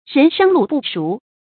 人生路不熟 注音： 讀音讀法： 意思解釋： 比喻初到一個地方各方面都很陌生。